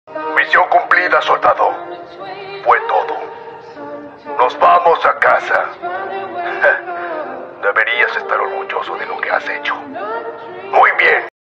mision cumplida soldado Meme Sound Effect